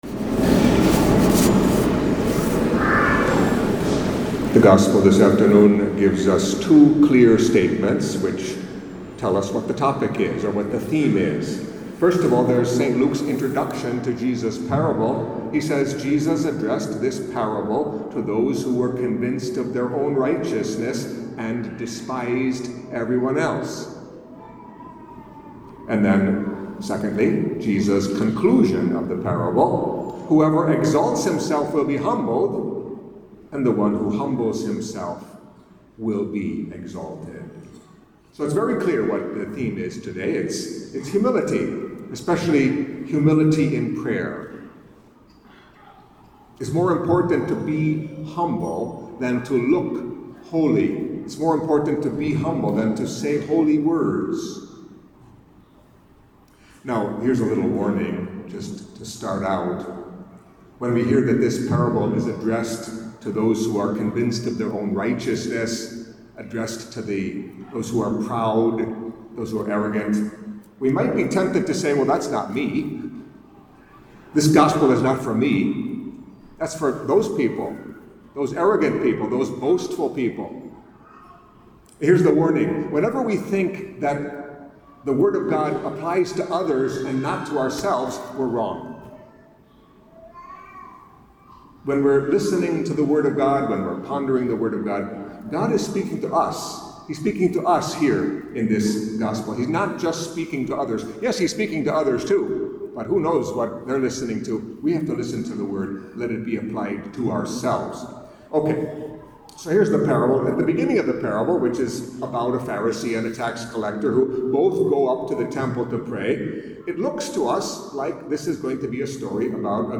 Catholic Mass homily for Thirtieth Sunday in Ordinary Time